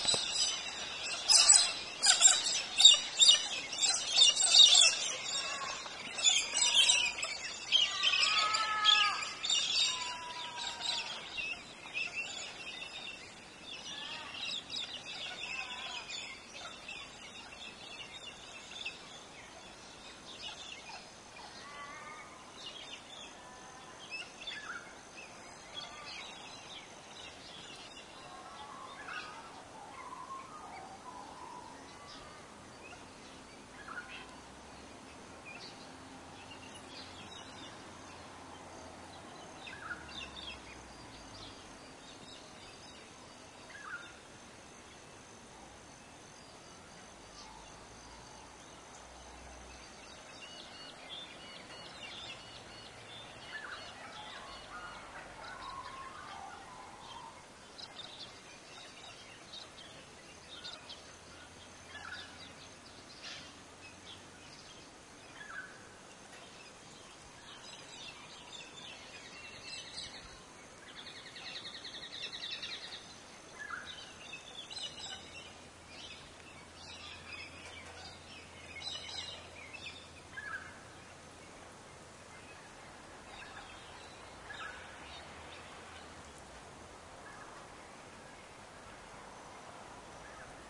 自然 " 鸟鸣
描述：从新南威尔士州中部海岸新贝林根内陆的澳大利亚丛林中记录的鸟鸣声
标签： 威尔士 灌木 昆虫 自然 澳大利亚 气氛
声道立体声